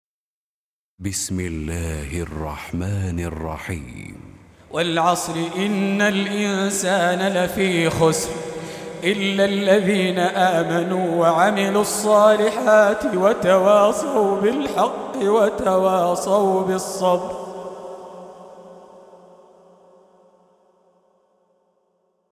Surah Repeating تكرار السورة Download Surah حمّل السورة Reciting Murattalah Audio for 103. Surah Al-'Asr سورة العصر N.B *Surah Includes Al-Basmalah Reciters Sequents تتابع التلاوات Reciters Repeats تكرار التلاوات